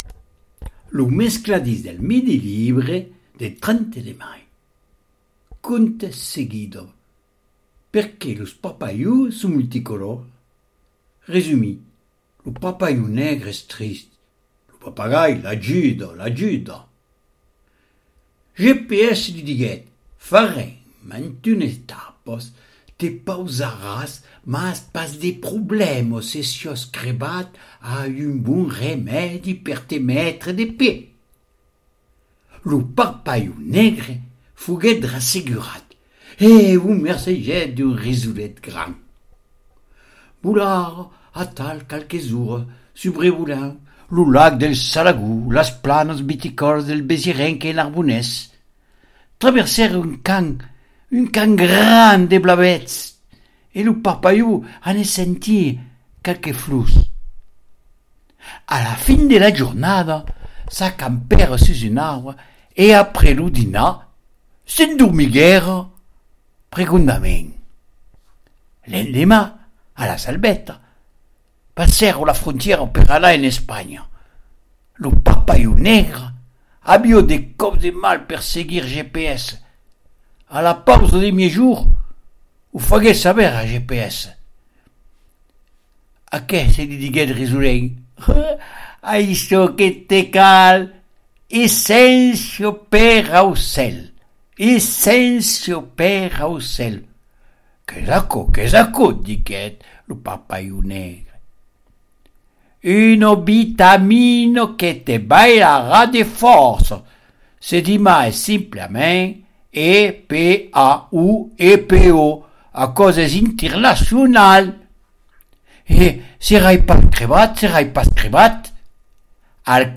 Conte : Perqué los parpalhons son multicolòrs ?